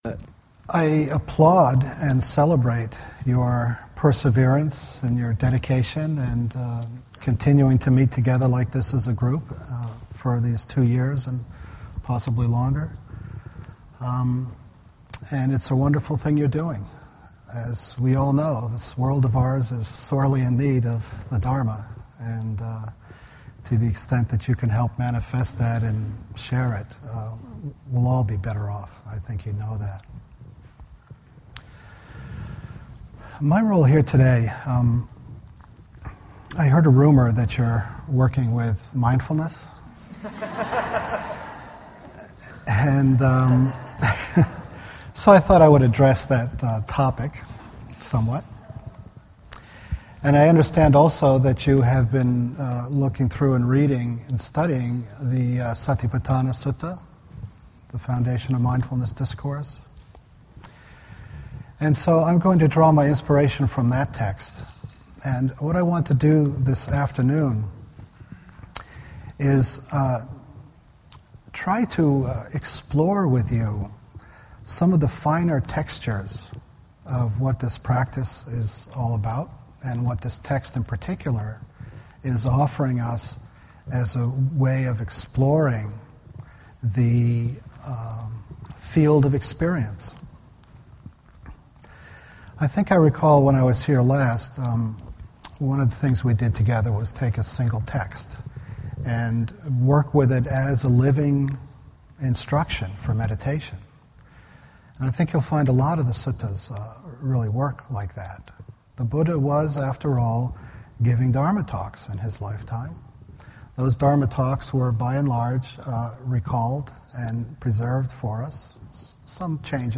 Talk given at the Community Meditation Center in New York City on Sunday, September 17, 2017.